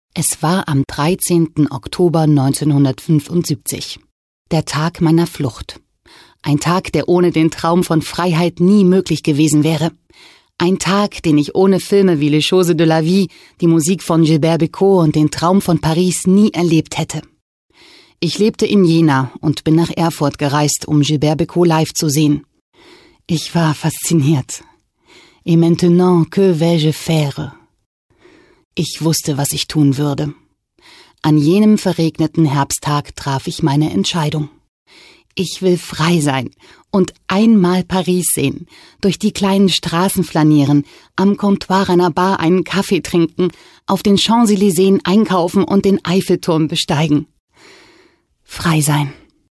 Booking Sprecherin